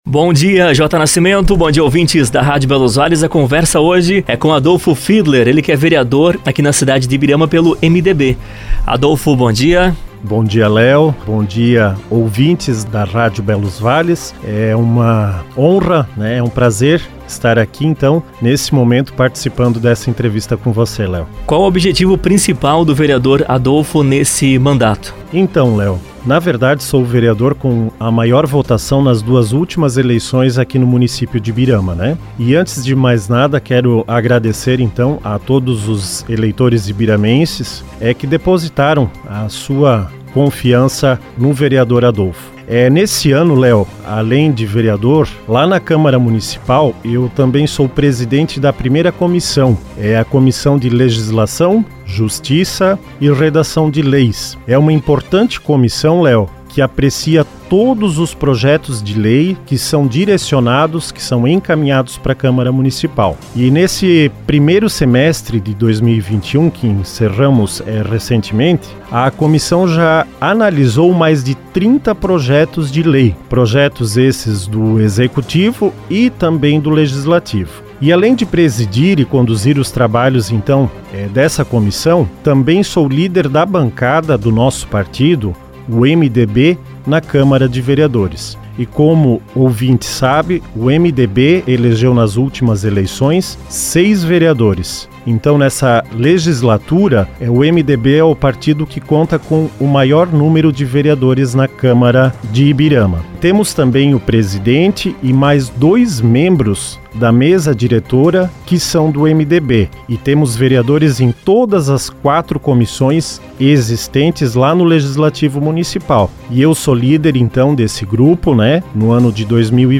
Ibirama: Entrevista com o Vereador Adolfo Fiedler do MDB